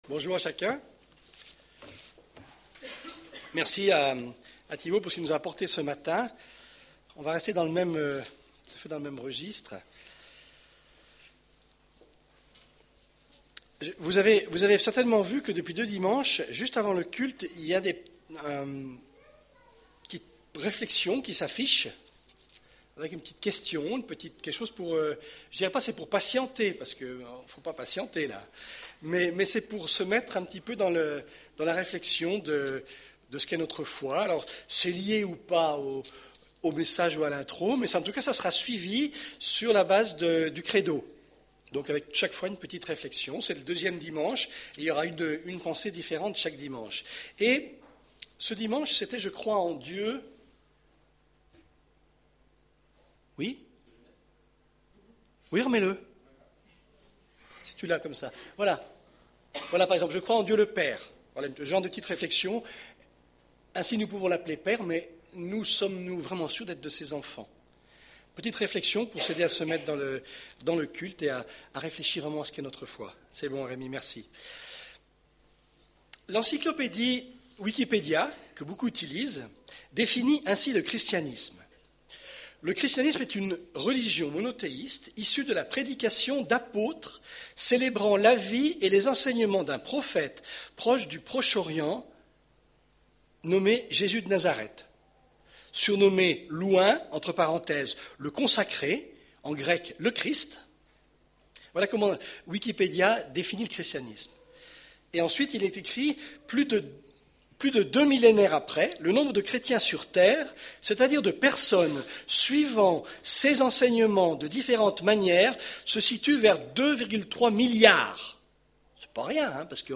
Culte du 11 mars